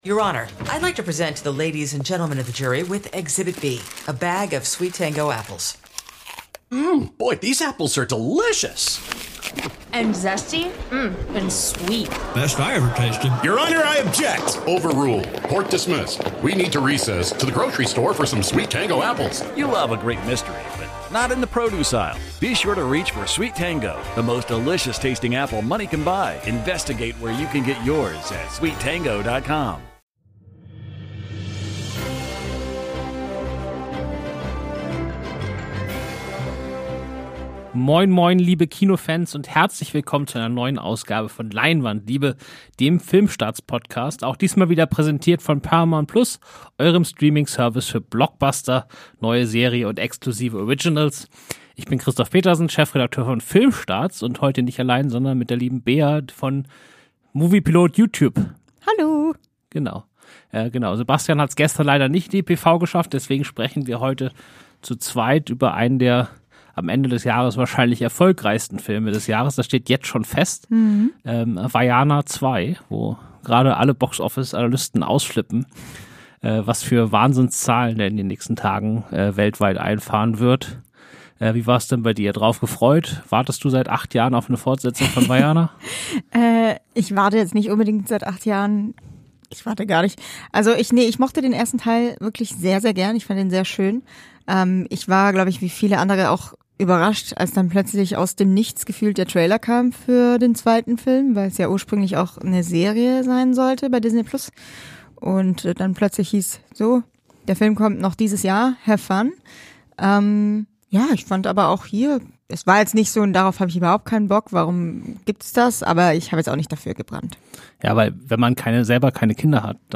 1 Spezial - Interview mit Veronika Franz & Severin Fiala (Des Teufels Bad / The Devil's Bath) 49:29